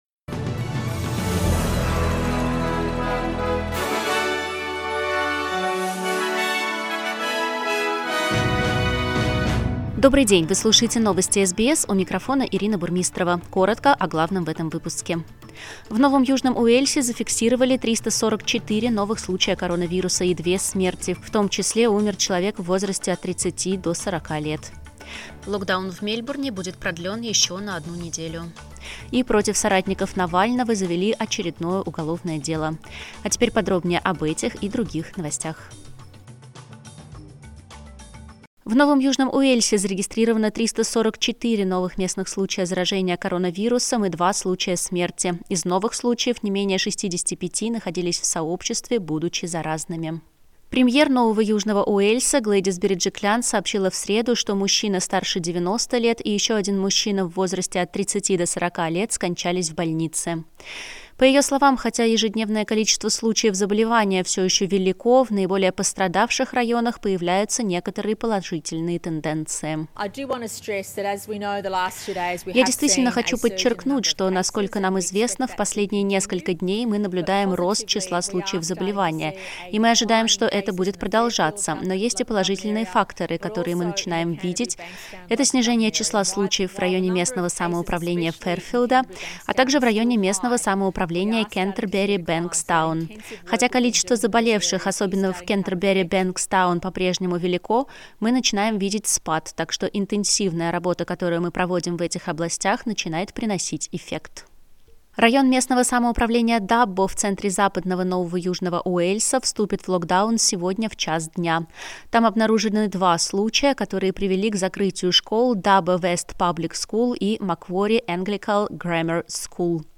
Новости SBS на русском языке - 11.08